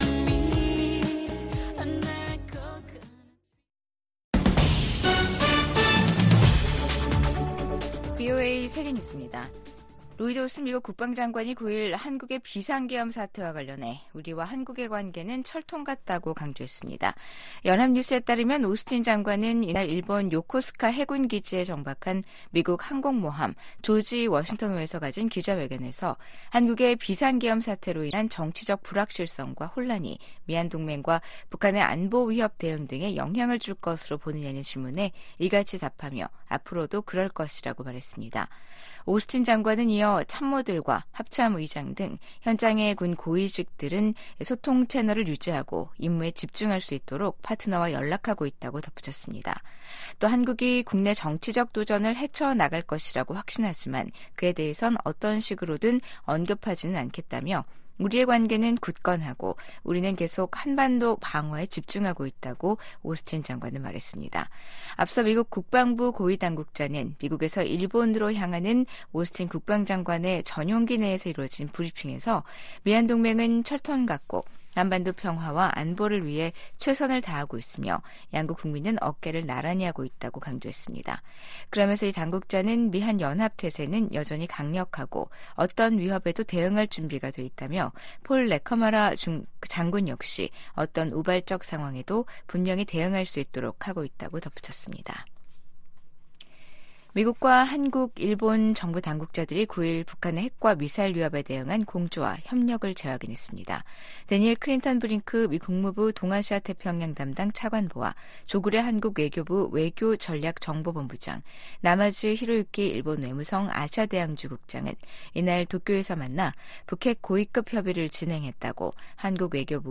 VOA 한국어 아침 뉴스 프로그램 '워싱턴 뉴스 광장'입니다. 윤석열 한국 대통령이 비상계엄 선포 행위로 내란 혐의 피의자가 되면서 국정 공백에 대한 우려가 커지고 있습니다. 미국 정부는 윤석열 대통령의 단호한 대북·대중 정책과 우호적 대일 정책이 탄핵 사유로 언급된 것과 관련해, 한국 민주주의 체제의 원활한 운영이 중요하다는 입장을 재확인했습니다.